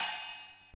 Miss Me OpenHat.wav